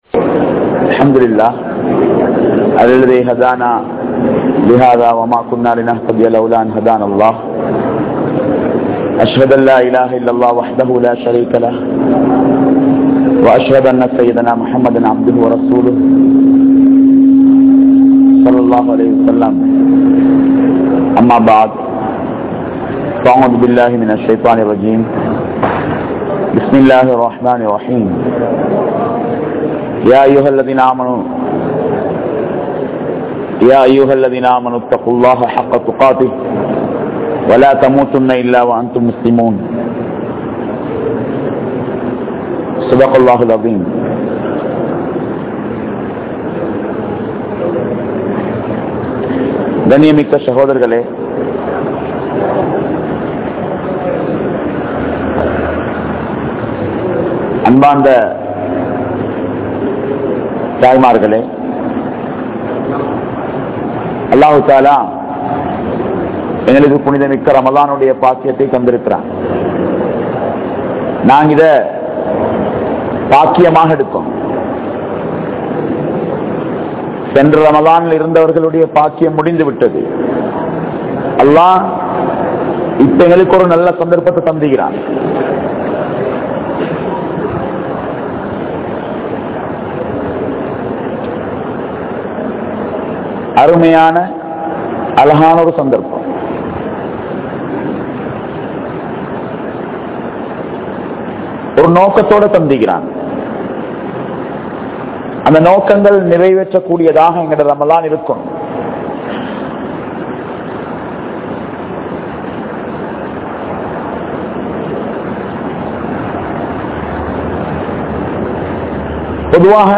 Vaalifarhalin Muthal Soathanai Pengal | Audio Bayans | All Ceylon Muslim Youth Community | Addalaichenai